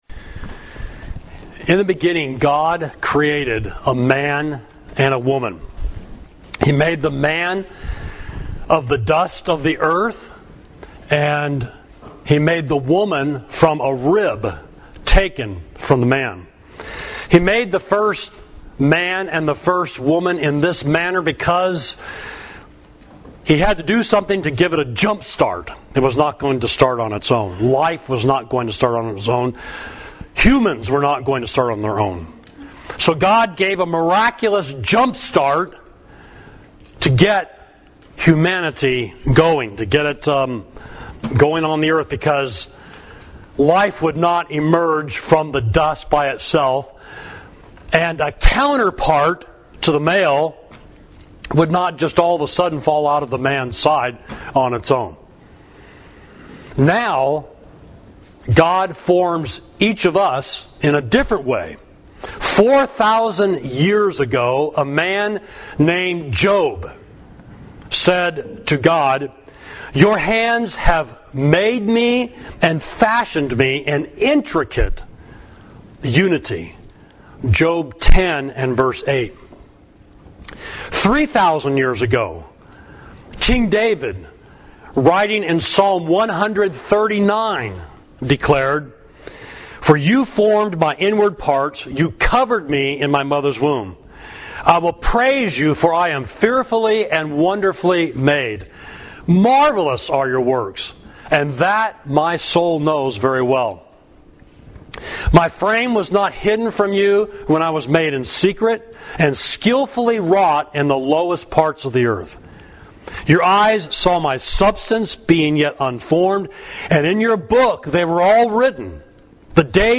Sermon: From Formation to Deformation to Information to Transformation – Savage Street Church of Christ